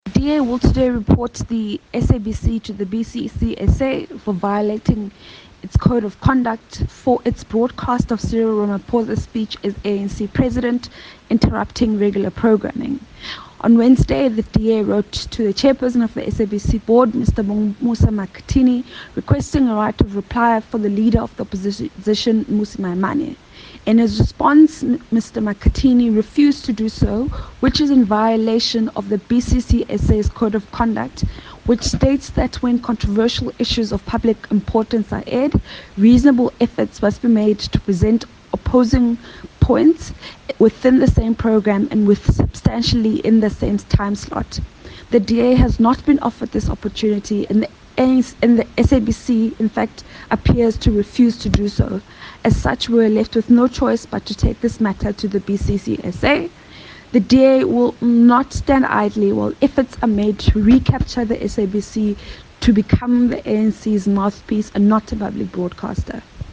soundbite by the DA Shadow Minister of Communications, Phumzile Van Damme MP.
Phumzile-Van-Damme-DA-Refers-SABC-to-BCCSA.mp3